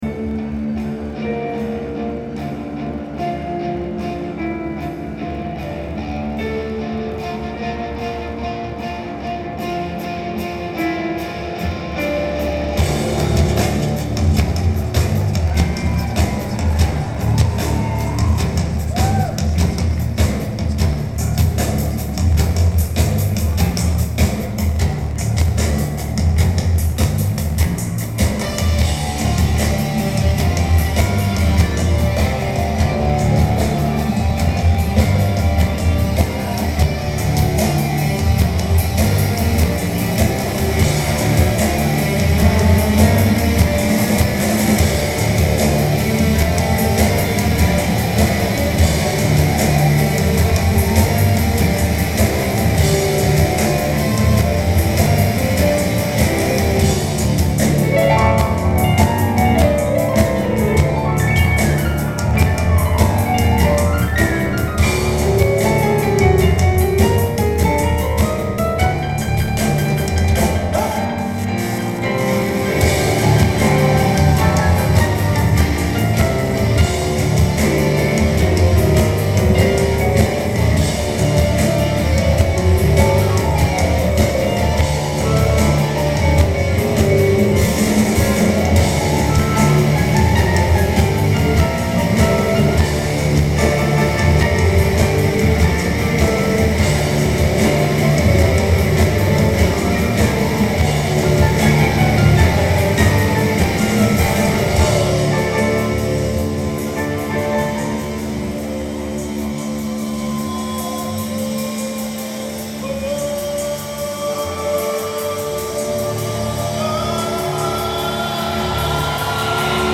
The Wiltern Theatre
Los Angeles, CA United States
Drums
Bass
Guitar
Vocals/Guitar/Keyboards
Lineage: Audio - AUD (Edirol R09 + Internal Mics)